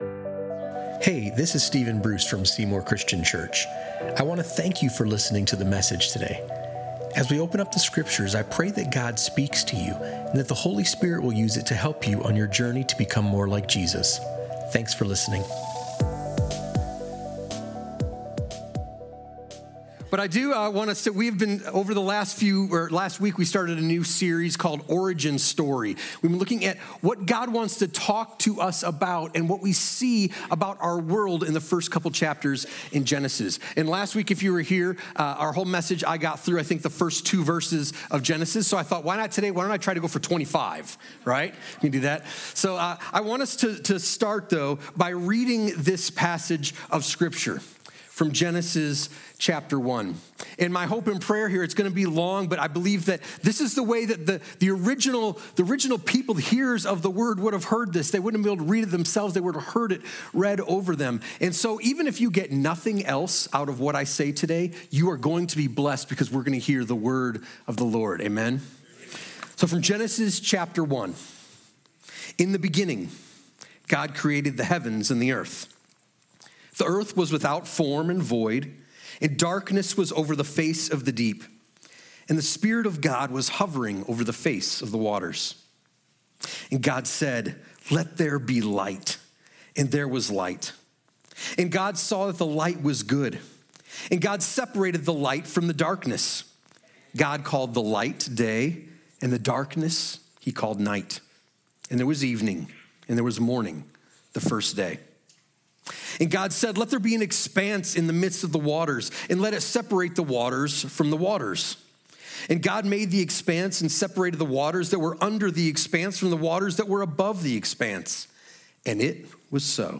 A Genesis 1:3–25 message on God’s voice, order, power, and your value.